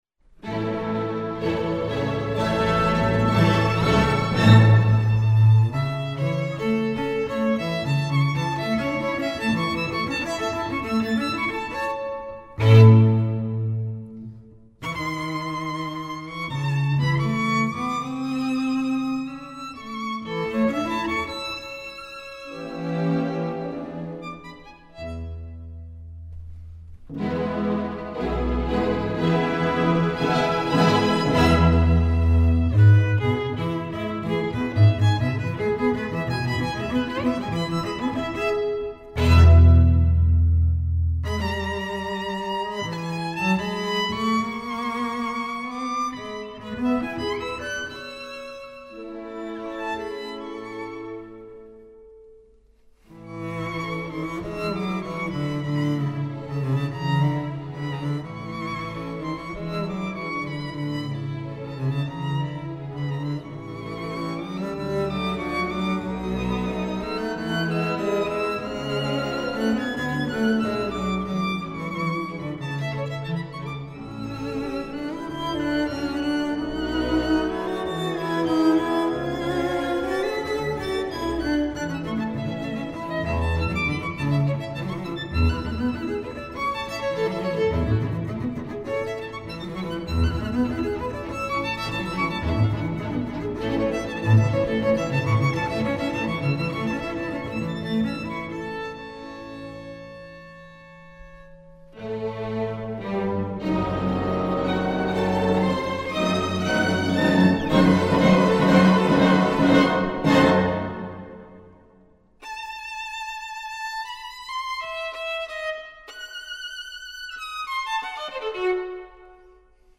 for violin and double bass